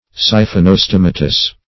Meaning of siphonostomatous. siphonostomatous synonyms, pronunciation, spelling and more from Free Dictionary.
Search Result for " siphonostomatous" : The Collaborative International Dictionary of English v.0.48: Siphonostomatous \Si`pho*no*stom"a*tous\, a. (Zool.)